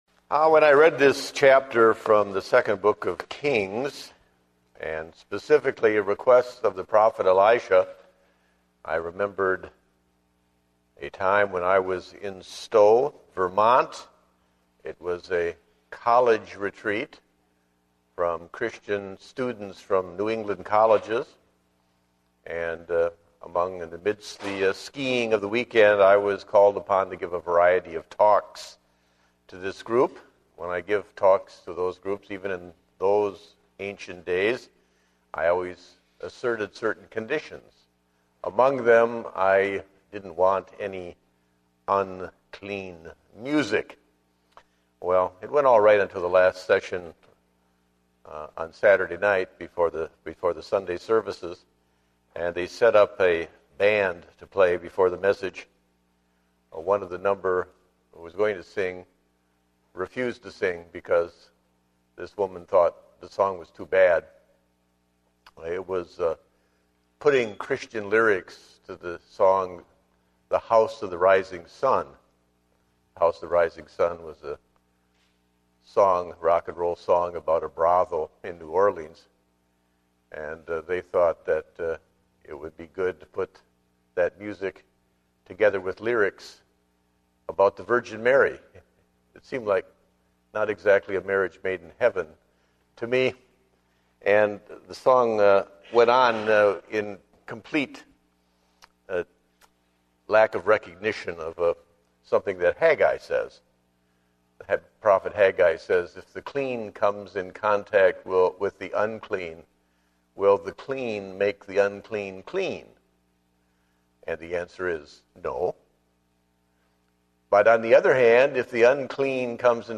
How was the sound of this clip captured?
Date: May 16, 2010 (Evening Service)